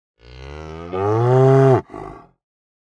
دانلود آهنگ مزرعه 11 از افکت صوتی طبیعت و محیط
دانلود صدای مزرعه 11 از ساعد نیوز با لینک مستقیم و کیفیت بالا
برچسب: دانلود آهنگ های افکت صوتی طبیعت و محیط دانلود آلبوم صدای مزرعه روستایی از افکت صوتی طبیعت و محیط